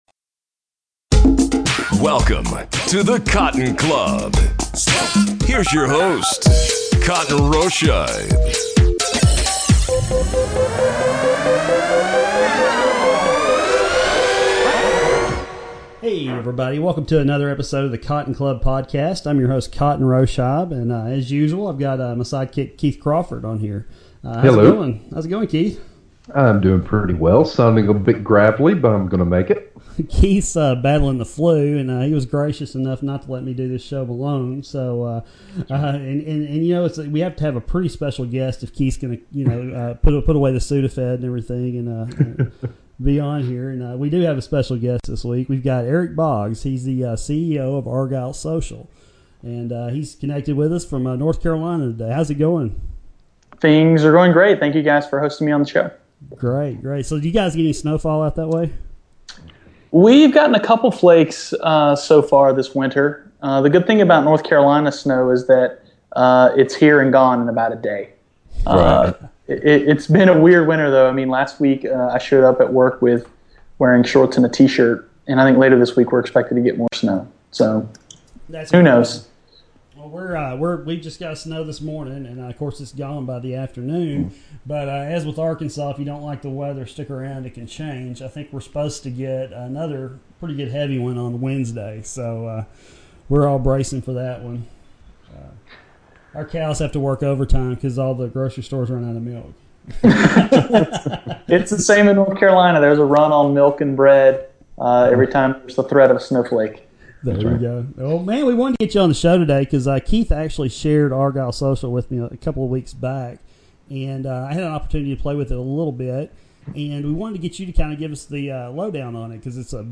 The end result was about a half hours worth of awesome conversation about Argyle’s product.